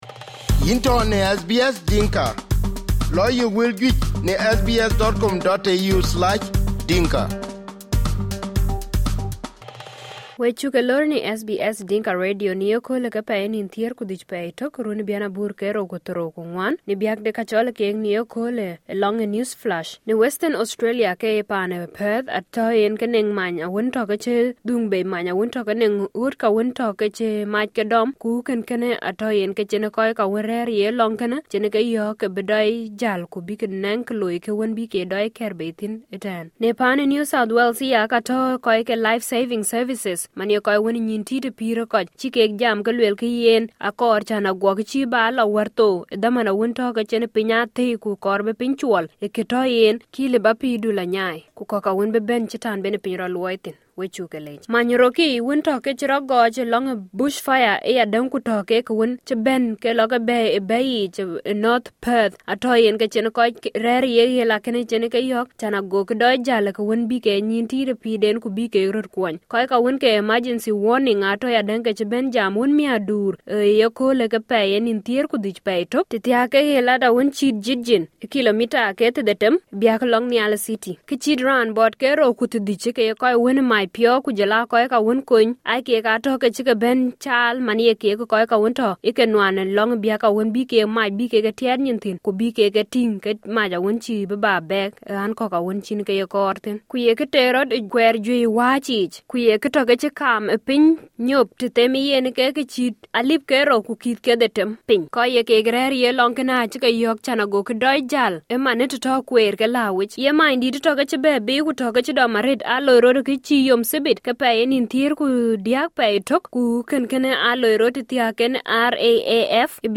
News Flash 15/01/2024